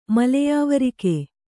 ♪ maleyāvarike